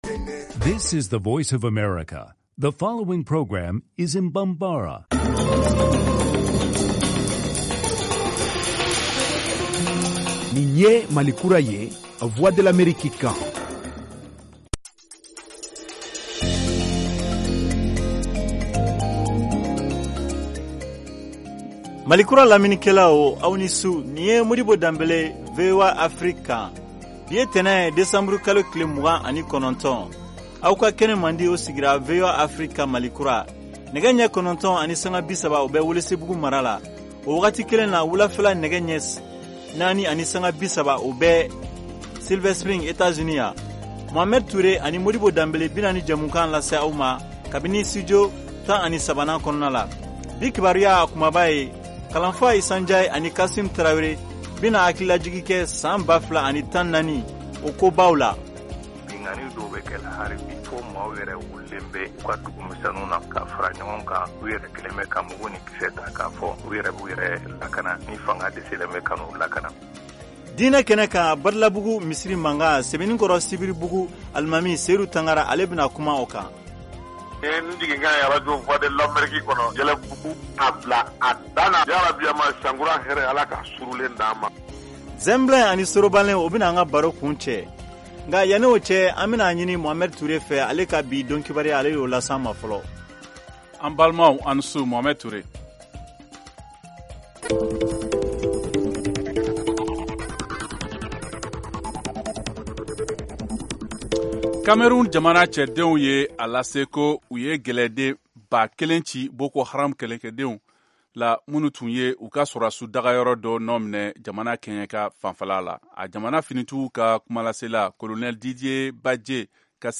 Emission quotidienne
en direct de Washington, DC, aux USA.